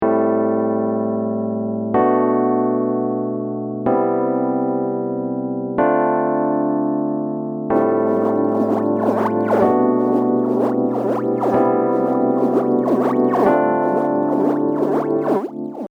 ゆったりさせるとふんわりとしたモジュレーションになってこれはこれで味があるかもしれません。